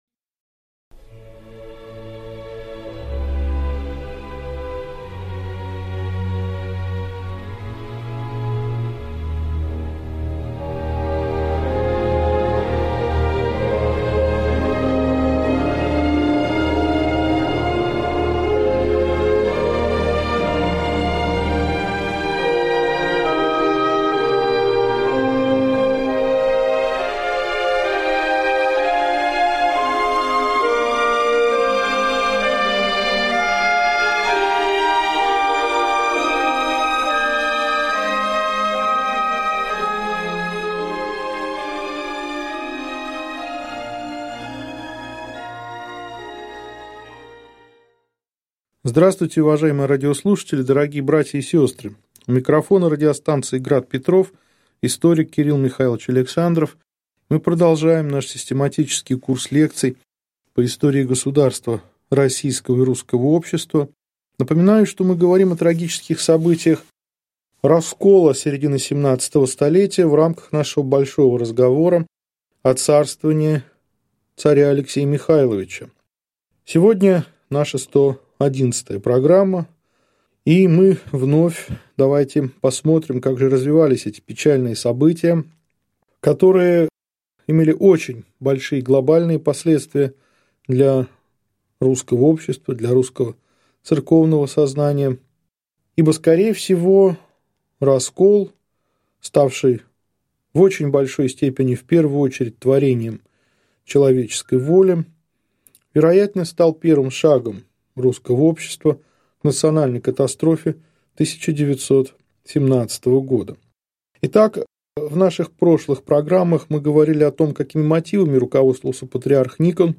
Аудиокнига Лекция 111. Начало церковного раскола | Библиотека аудиокниг